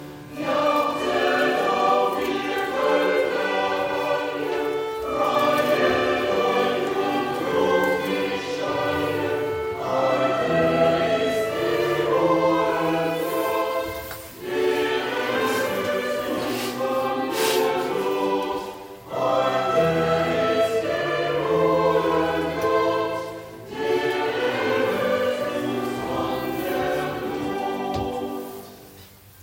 Musikalische Mitgestaltung durch unsere neue Singgemeinschaft
Das Patrozinium Hl. Stephanus am 26. Dezember ist jedes Jahr ein Festtag für unsere Pfarrgemeinde Schönering.
Heuer probten 15 Singfreudige und Musikanten